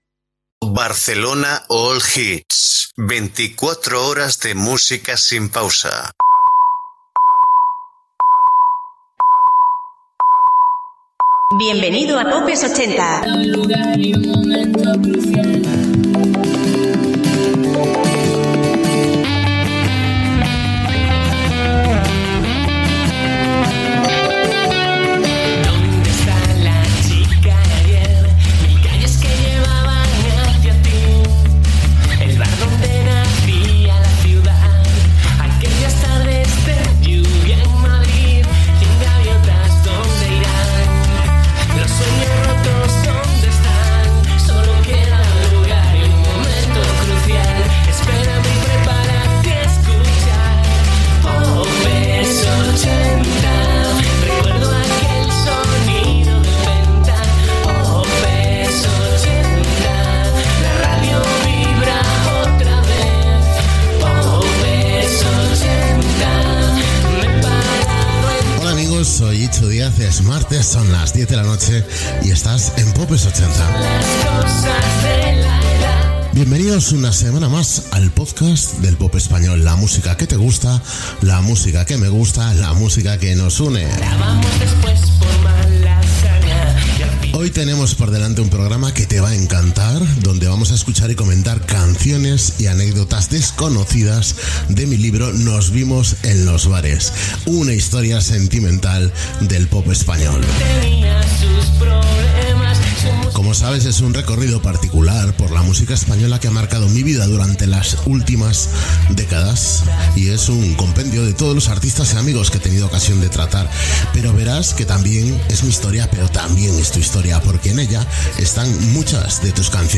Musical
Indicatiu de la ràdio, sintonia, presentació del programa, el llibre "Nos vimos en los bares: una historia sentimental del pop español"